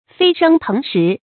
飛聲騰實 注音： ㄈㄟ ㄕㄥ ㄊㄥˊ ㄕㄧˊ 讀音讀法： 意思解釋： 飛：飛揚；騰：上升。指名聲和實際都好。